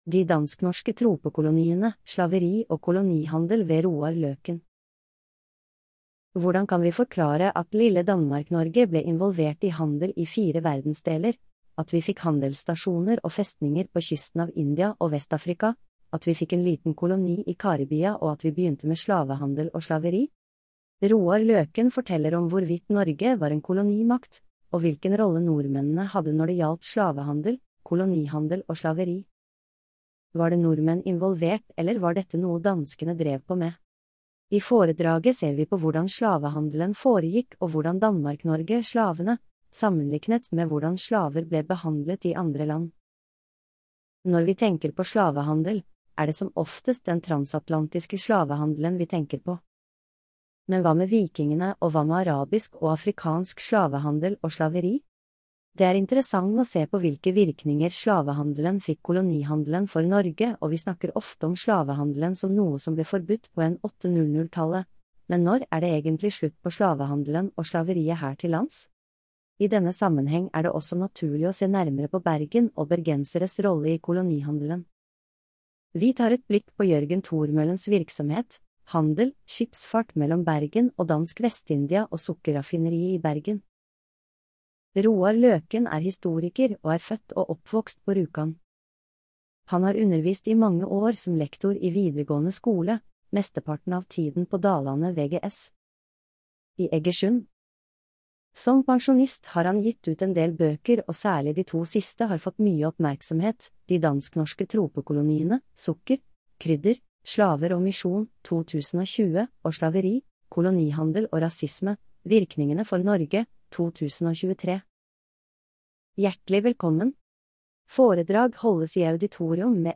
Velkommen til mandagsforedrag!